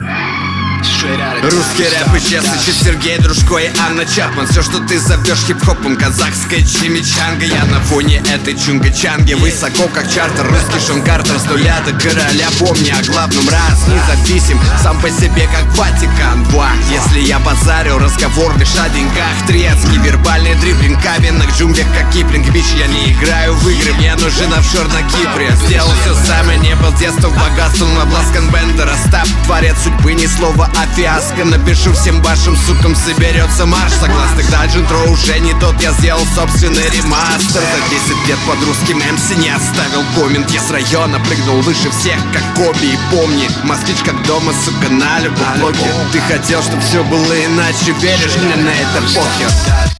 Очень плотно набит и зачитан прилично.